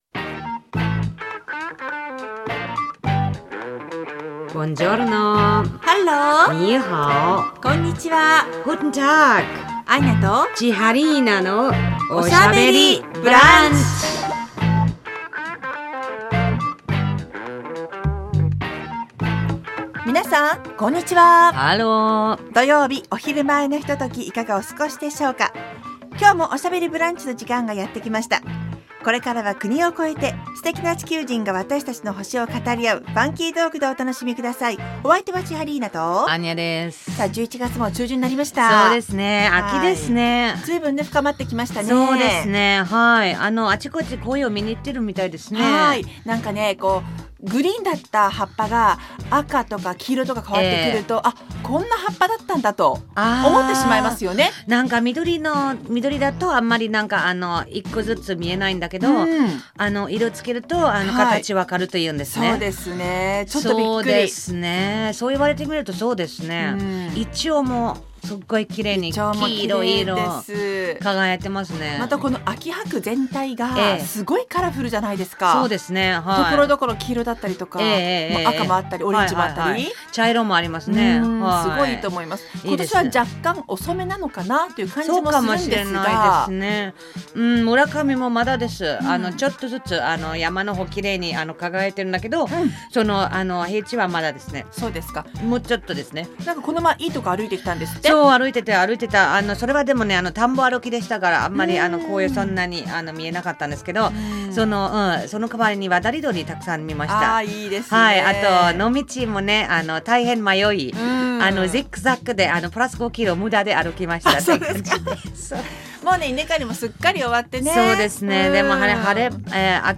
放送された内容を一部編集してお送りします。